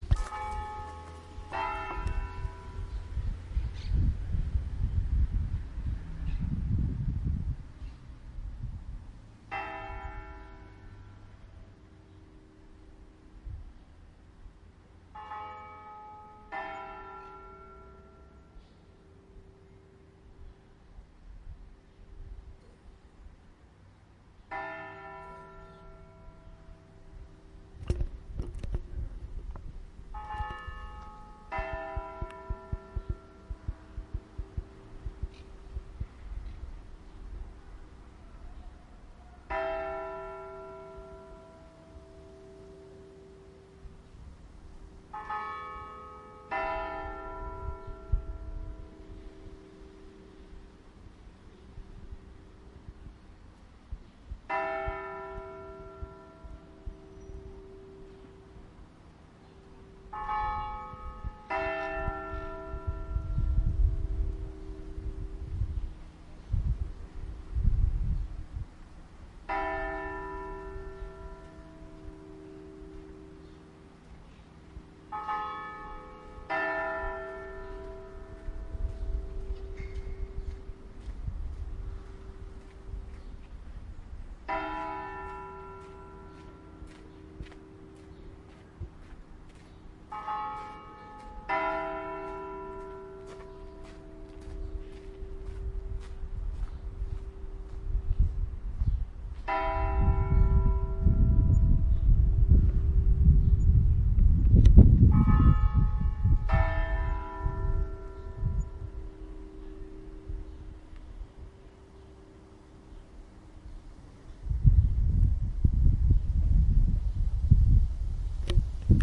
描述：Campanes del monestir de Sant Cugat
Tag: 葬礼 恐惧 教堂的钟声